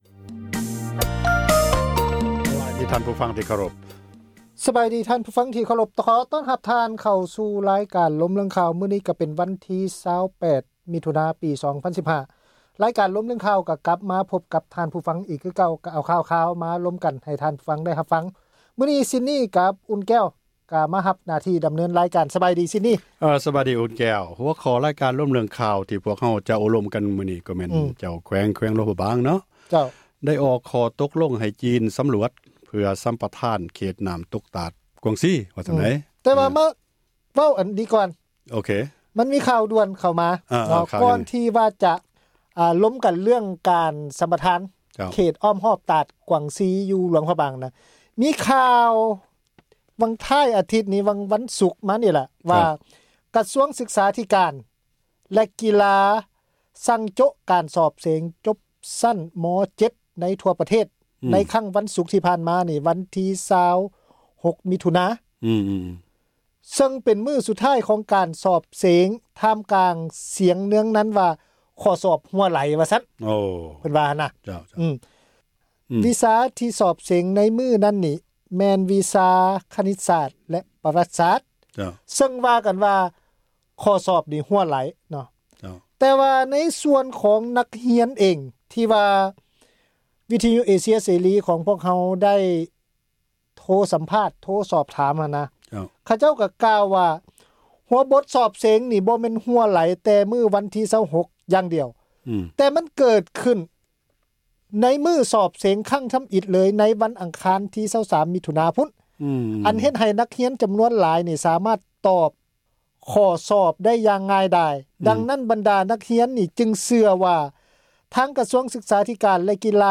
ການສົນທະນາ ເຖິງເຫດການ ທີ່ເກີດຂຶ້ນ ໃນແຕ່ລະມື້ ທີ່ມີຜົນກະທົບ ຕໍ່ຊີວິດປະຈໍາວັນ ຂອງຊາວລາວ ທົ່ວປະເທດ ທີ່ ປະຊາສັງຄົມ ເຫັນວ່າ ຂາດຄວາມເປັນທັມ.